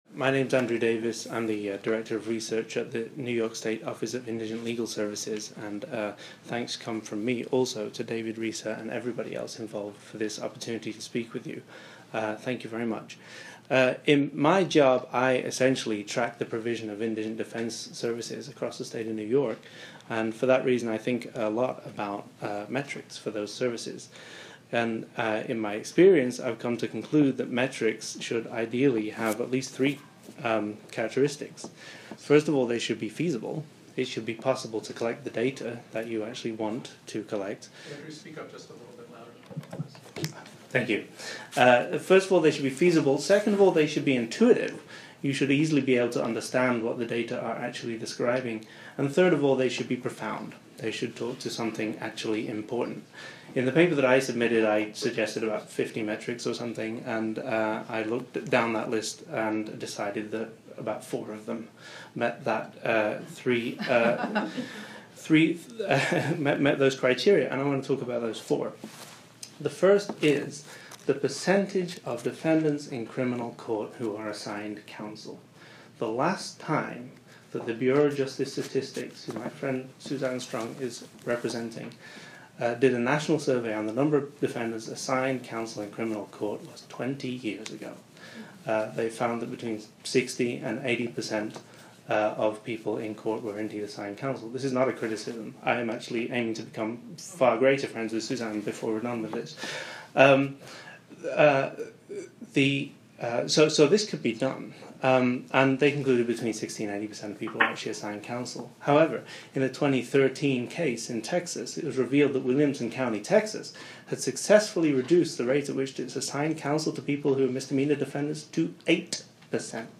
SDG Presentation Recording